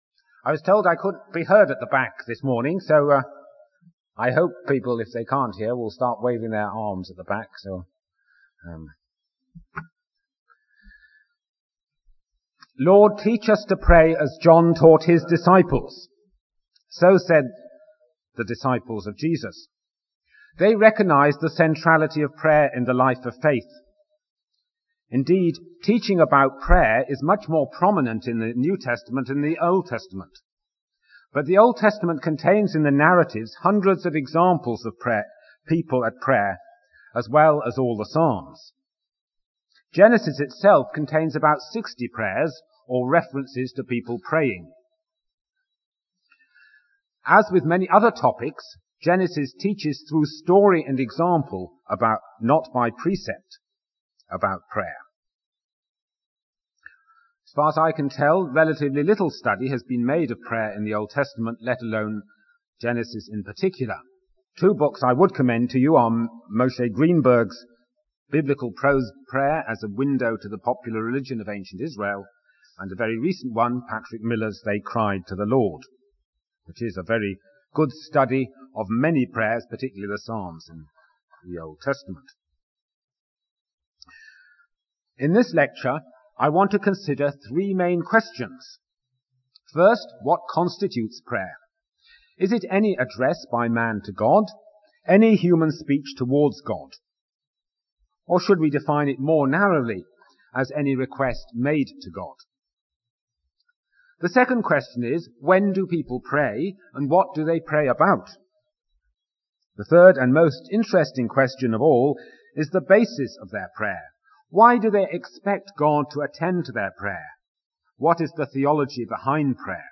Florida chapel services, 2002
Sermons